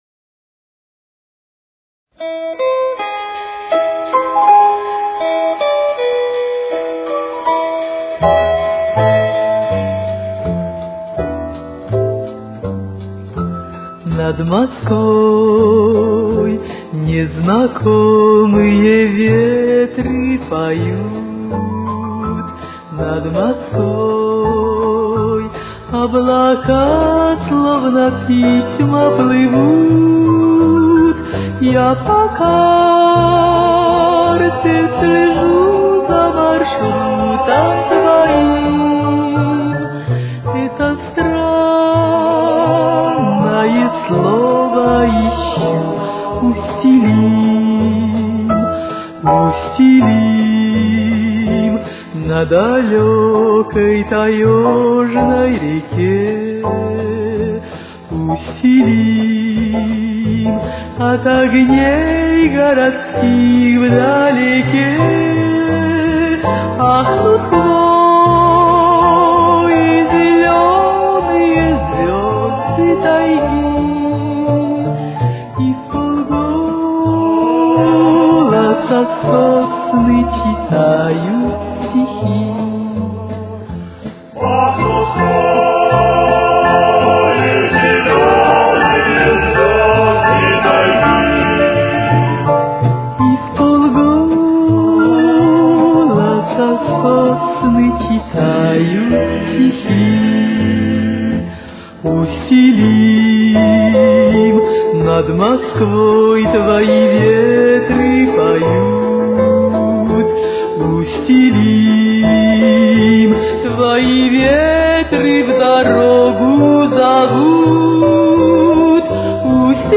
с очень низким качеством (16 – 32 кБит/с)
Темп: 84.